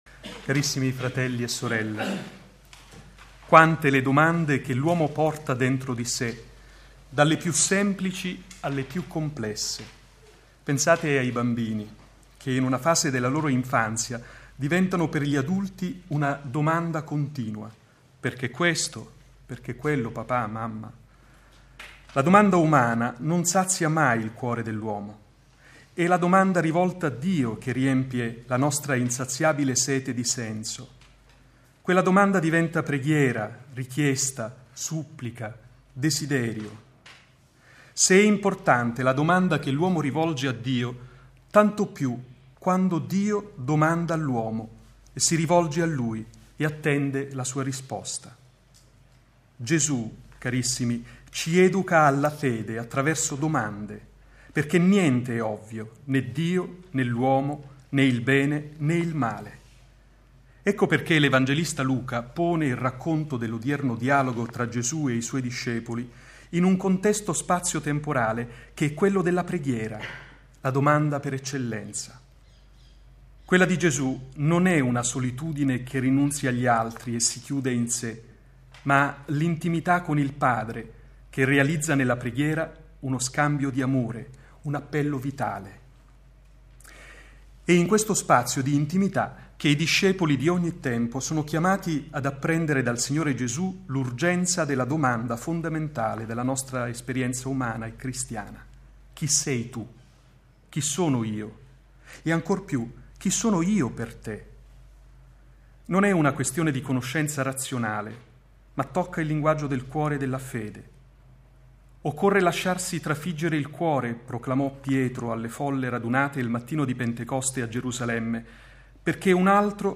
audio omelie:
Ogni Domenica alle 9.30 dalla Cappella Leone XIII all'interno dei Giardini Vaticani, viene trasmessa la Santa Messa secondo le intenzioni del Sommo Pontefice Benedetto XVI.